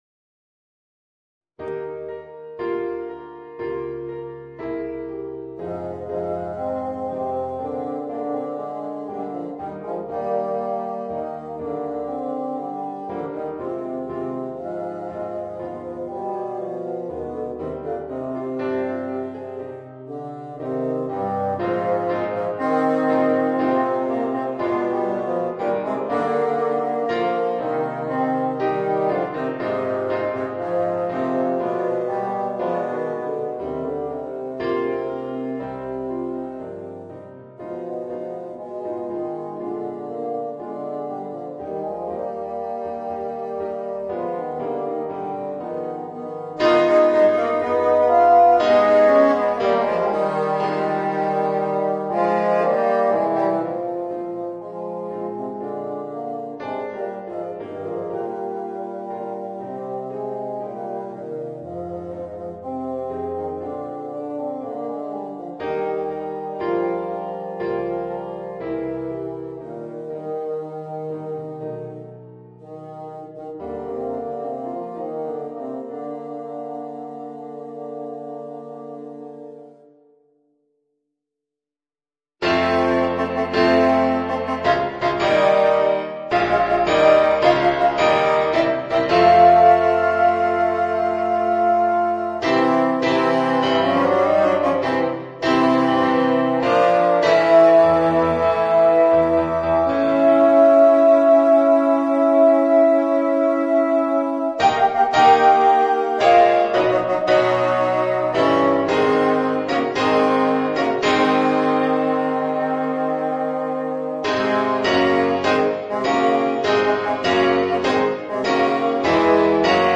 Voicing: 2 Bassoons and Piano